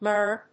/mˈɚː(米国英語), mˈəː(英国英語)/